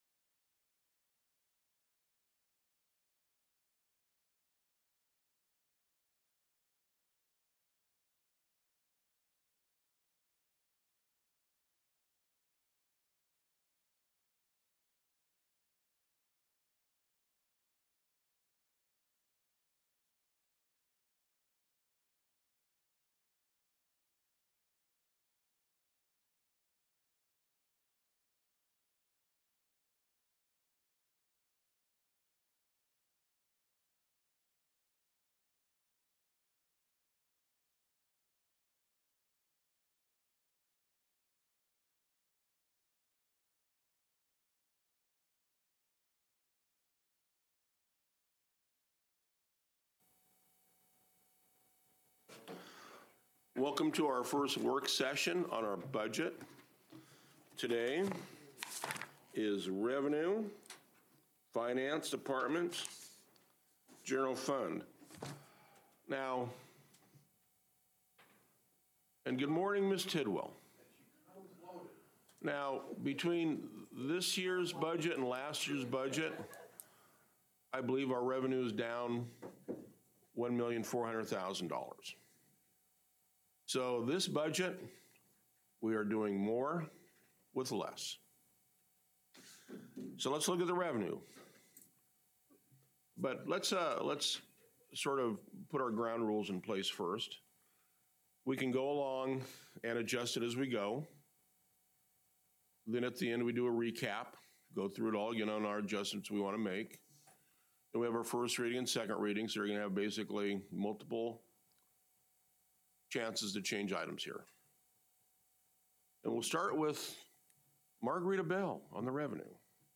Council Budget Meeting